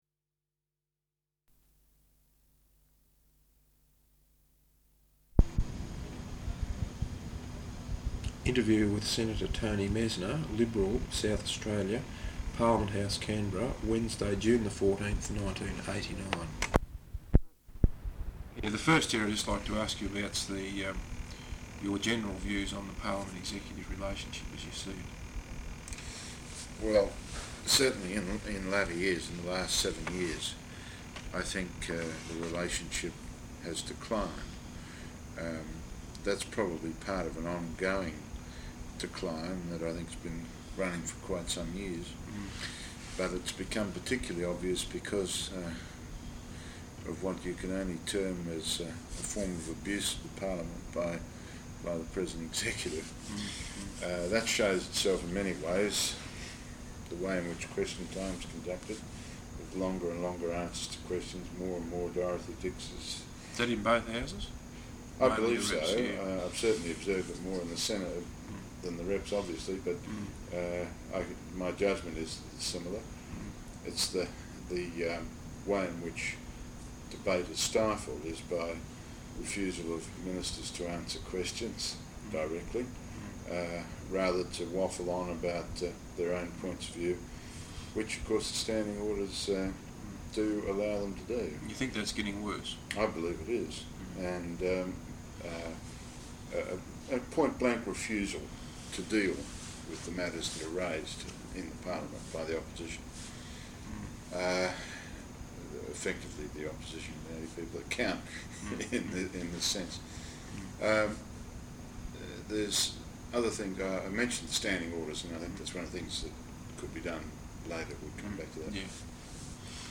Interview with Senator Tony Messner, Liberal Senator for South Australia. Parliament House, Canberra, Wednesday June 14th, 1989.